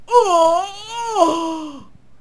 technician_die1.wav